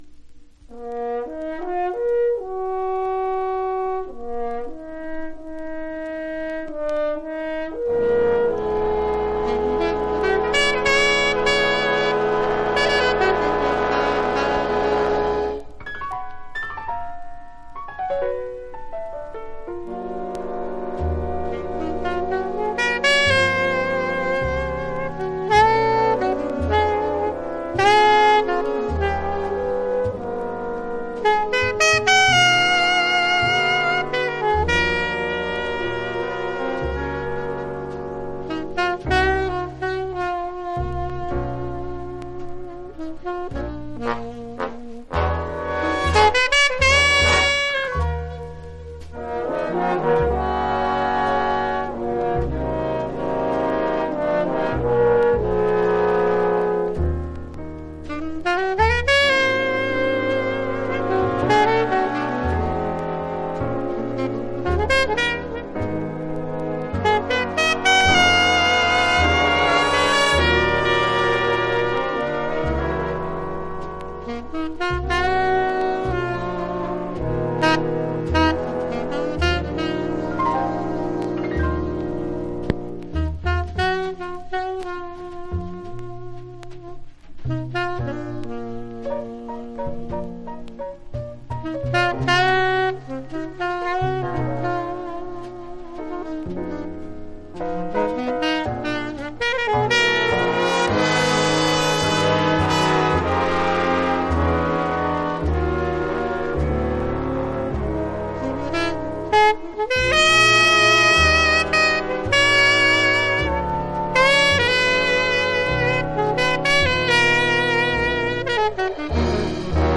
（小傷によりチリ、プチ音ある曲あり）
Genre US JAZZ